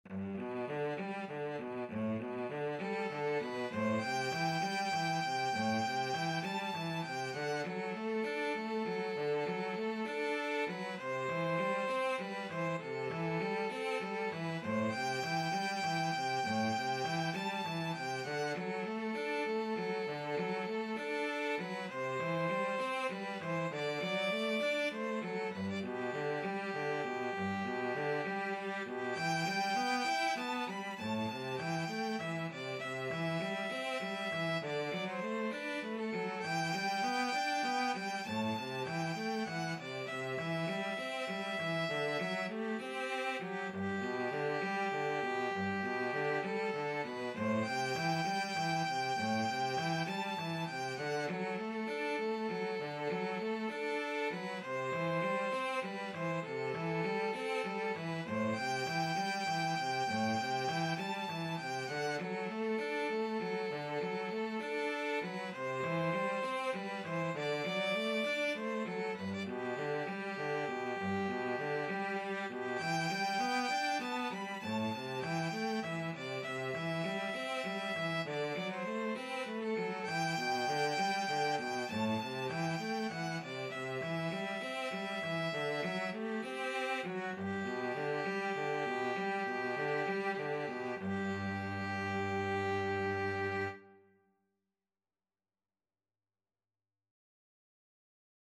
6/8 (View more 6/8 Music)
Traditional (View more Traditional Violin-Cello Duet Music)